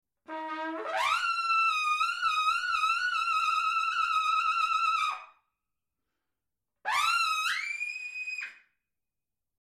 Zungentriller
f3, dann Glissando zum f4
zungentriller.mp3